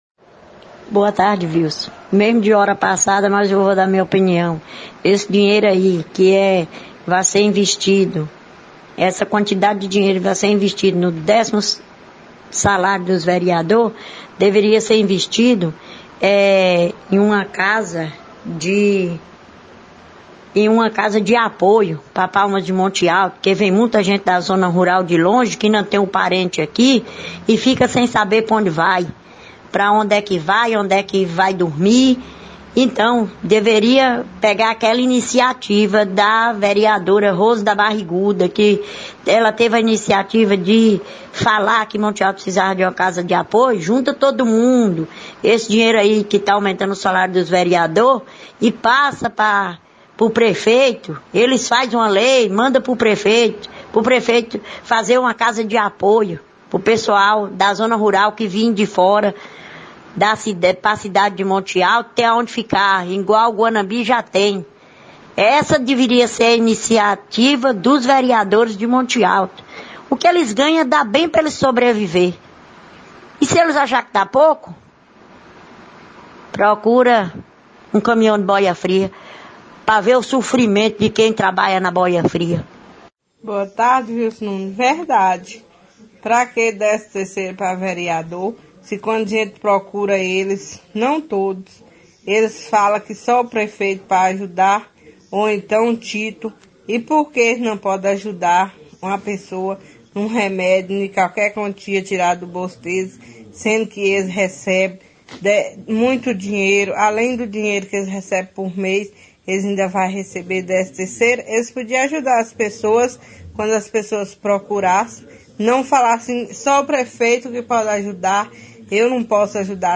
REPERCUSSÃO NA RÁDIO LOCAL
Diversos ouvintes encaminharam áudios ao programa Giro de Notícias da Rádio Visão FM demonstrando indignação com a aprovação da proposta que provocará uma despesa anual de R$ 83.563,37 (oitenta e três mil, quinhentos e sessenta e três reais e trinta e sete centavos), tendo em vista que atualmente o salário do vereador é de R$ 7.596,67 (sete mil, quinhentos e noventa e seis reais e sessenta e sete centavos), para trabalhar 3h semanais, conforme consta no site do Tribunal de Contas dos Municípios da Bahia (TCM-BA).